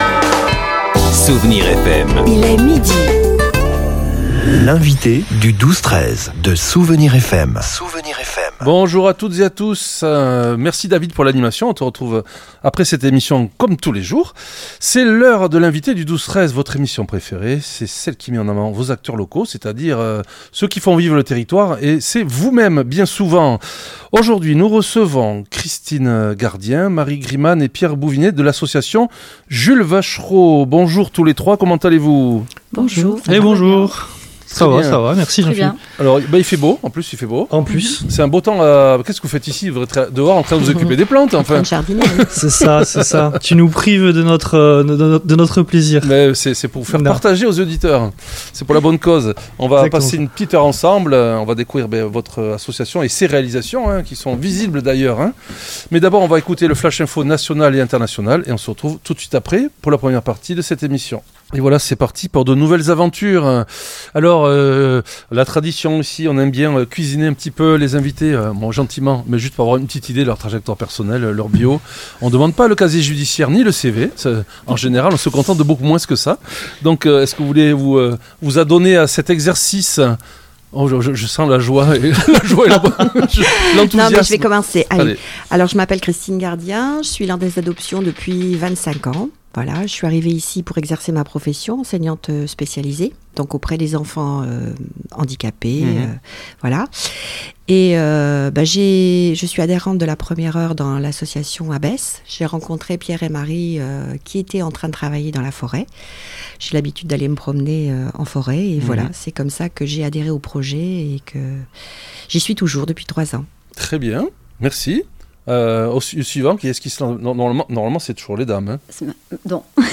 L'entretien a permis de lever le voile sur les découvertes de l'association, comme ce bassin de 5 000 litres enfoui sous la végétation, témoin d'une orangerie disparue.